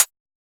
RDM_TapeA_SY1-ClHat.wav